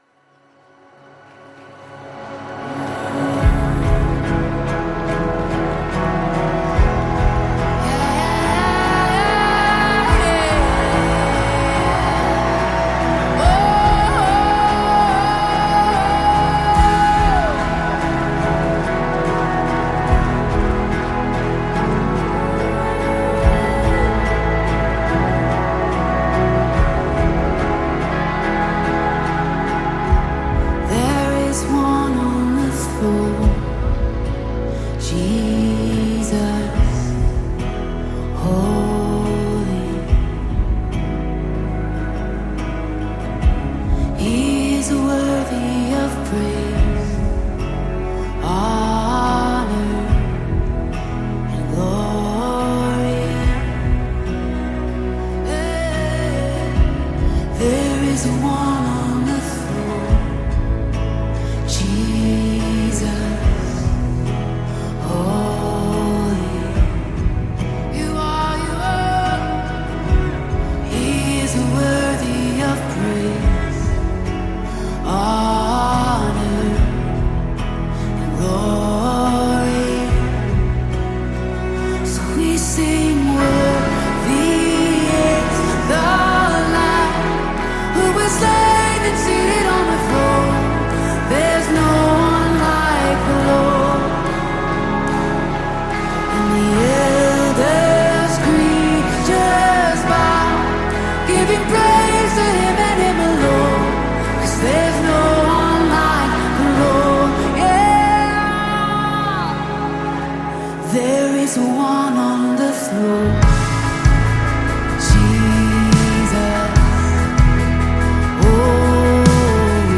4321 просмотр 1232 прослушивания 176 скачиваний BPM: 72